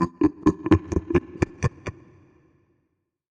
Evil Laugh_1
Creepy Evil Halloween Horror laugh Laugh menacing scary sound effect free sound royalty free Funny